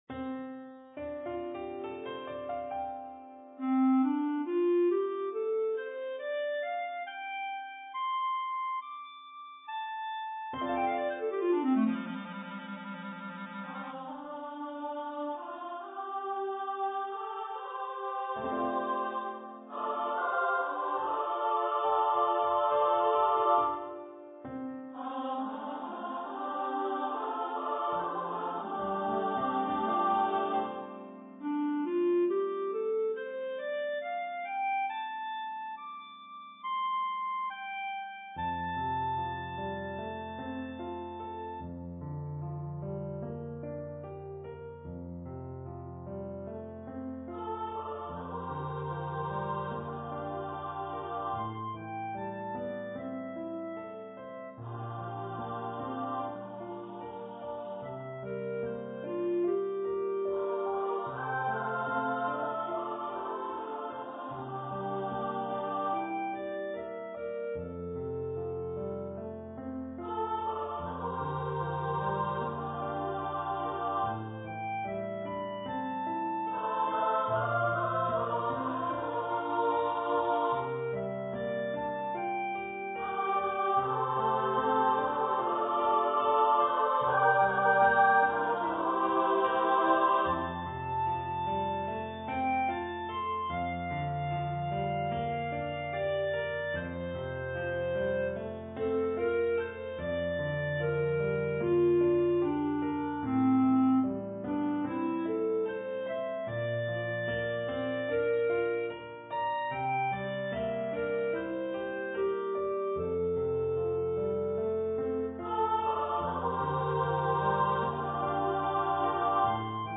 for SSA choir with clarinet and piano